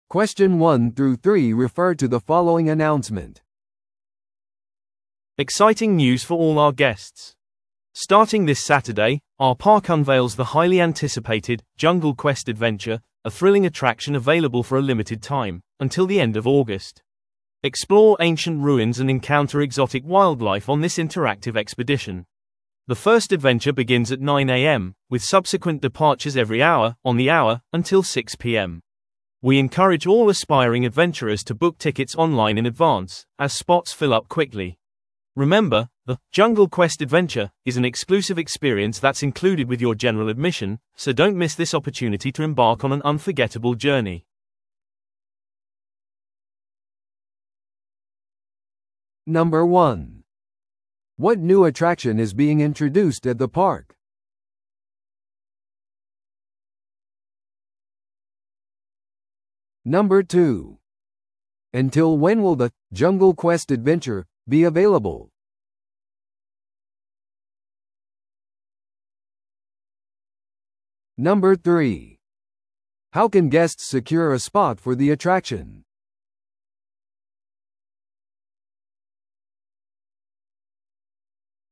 TOEICⓇ対策 Part 4｜限定アトラクションのお知らせ – 音声付き No.117